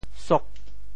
塾 部首拼音 部首 土 总笔划 14 部外笔划 11 普通话 shú 潮州发音 潮州 sog8 文 中文解释 塾 <名> (形声。